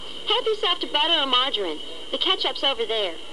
下列紅色部份為省音，已省去不唸；而字串連結為連音。